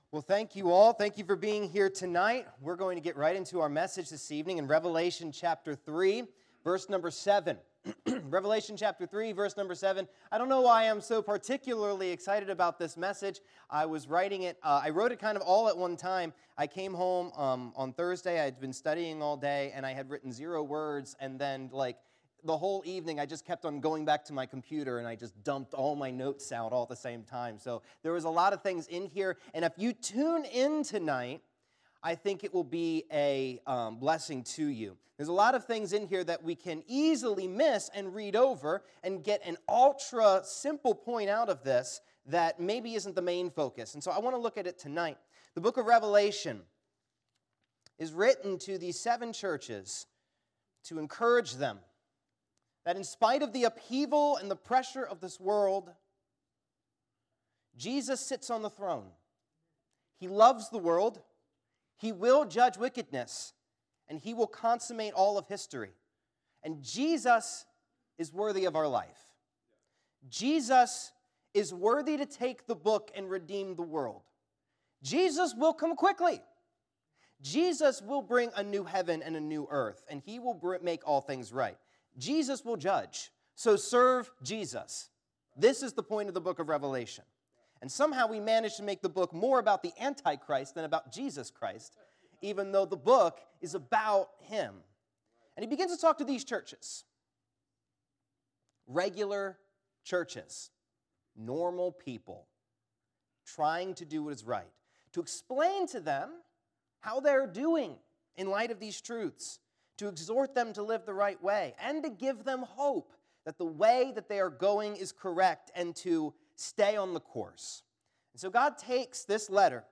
Passage: Revelation 3:7-10 Service Type: Sunday Evening